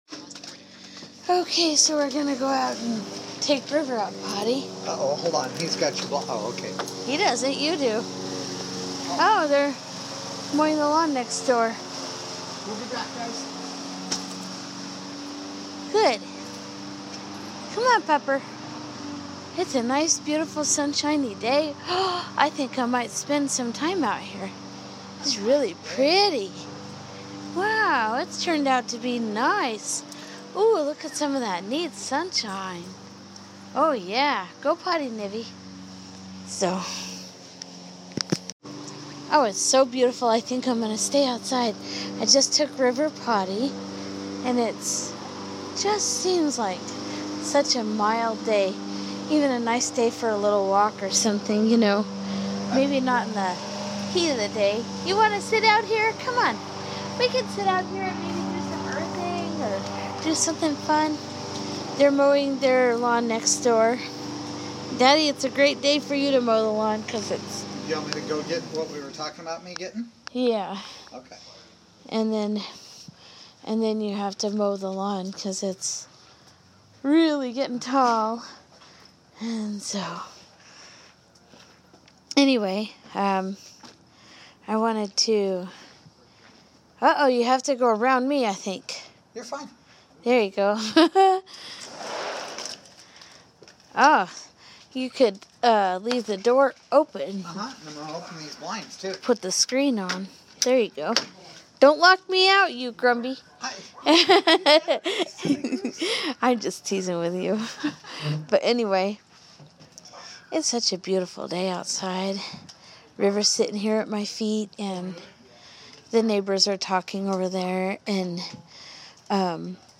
Talking about several different subjects on this nice beautiful sunny day with the lawnmower intermittently in the background.